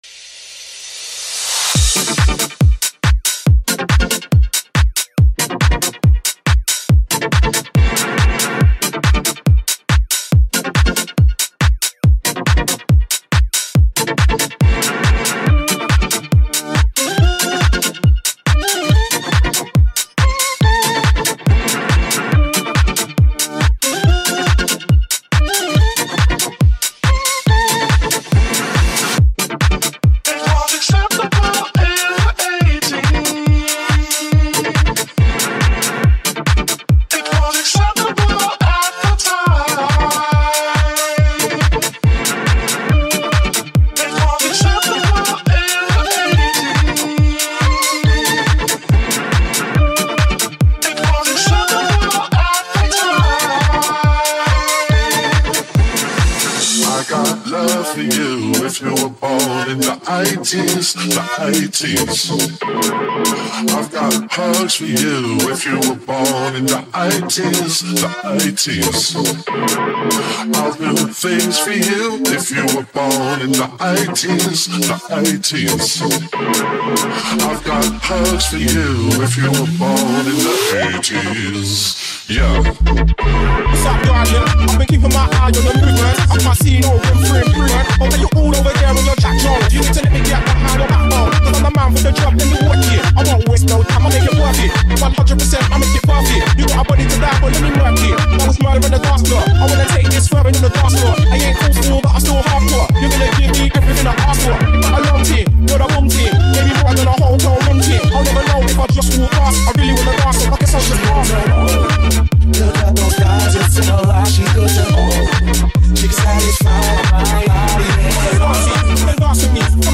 2024-10-16 19:35:58 Gênero: Electronic; Dance; Disco Views